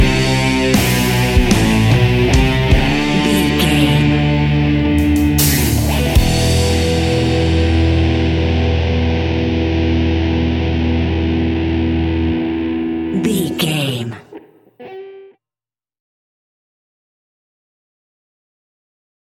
Epic / Action
Aeolian/Minor
hard rock
distortion
rock guitars
Rock Bass
heavy drums
distorted guitars
hammond organ